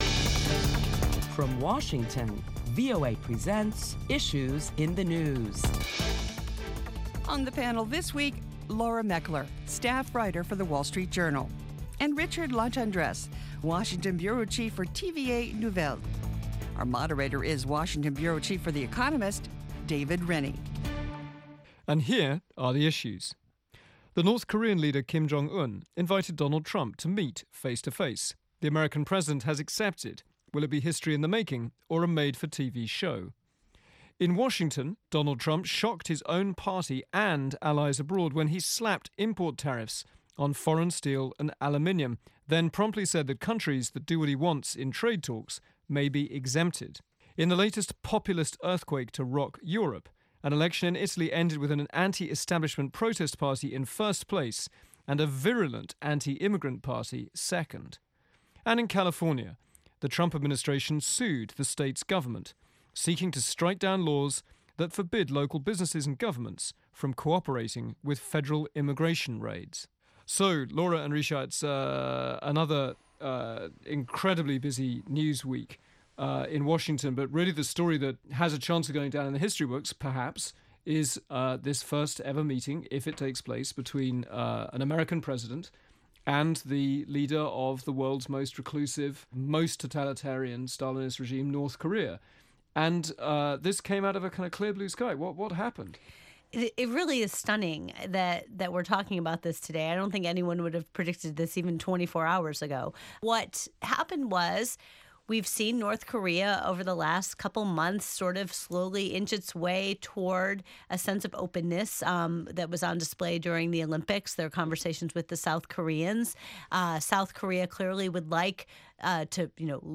Top Washington correspondents discuss President Trump's announcement that he accepted an offer of talks with North Korean Leader, Kim Jong Un.